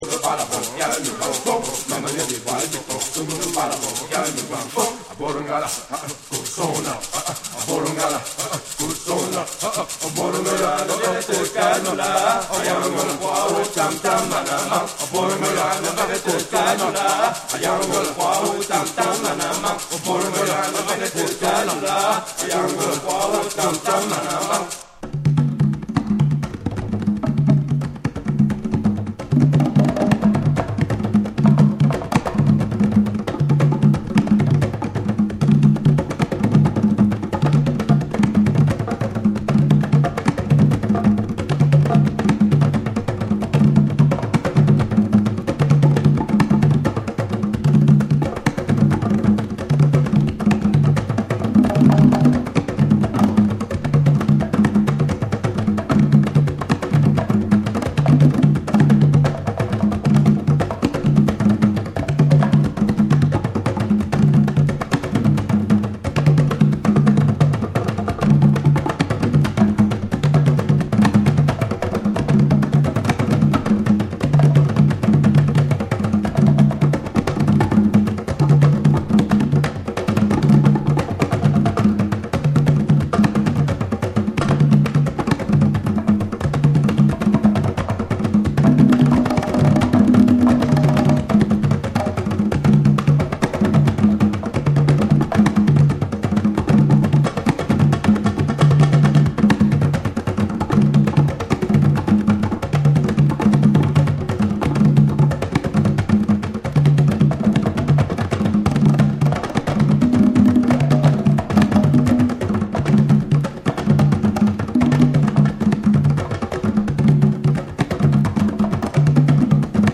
カメルーン出身の3兄弟によって結成されたパーカッション・トリオによる、圧倒的なグルーヴとリズムの魔術が詰まったアルバム。